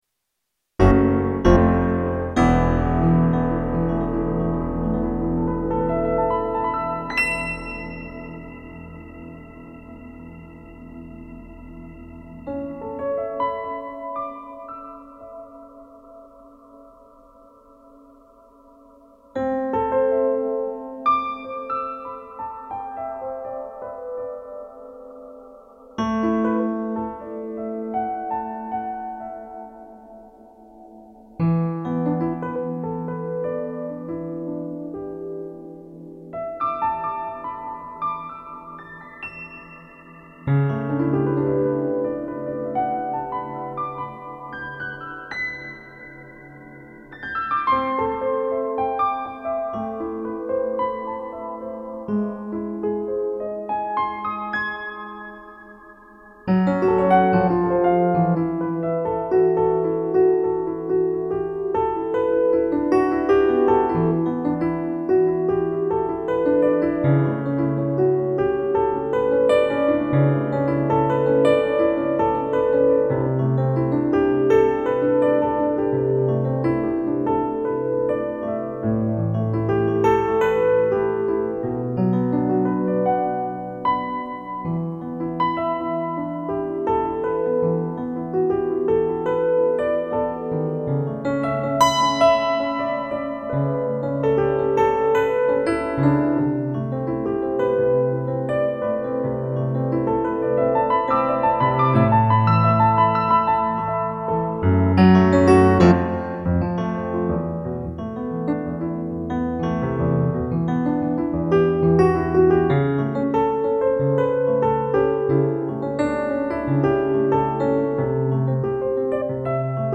I aimed to turn off my mind and play purely from the moment, but my usual playing habits held me back.
Harmonical and rhythmical mistakes kept pulling me out of the zone, reminding me that improvisation isn’t easy.